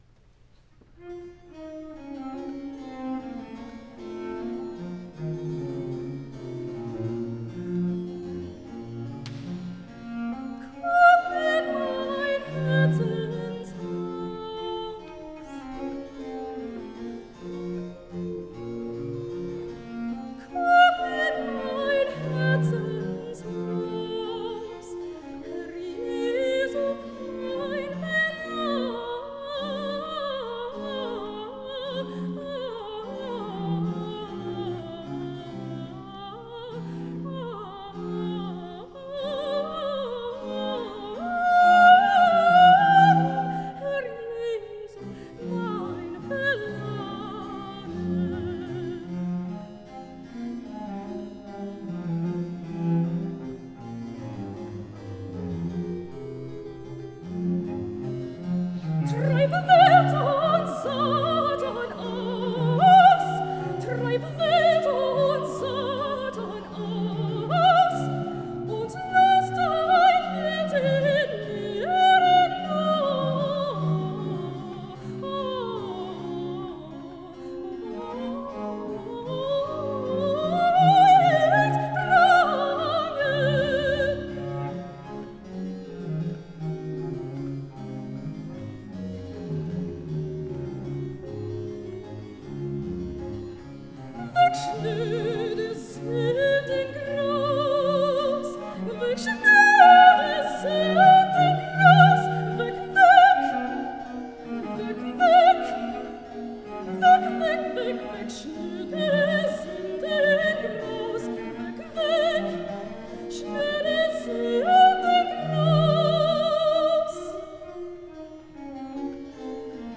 soprano
Solo song:
Komm in mein Herzenshause, BWV 80, conducted by Masaki Suzuki